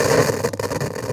radio_tv_electronic_static_19.wav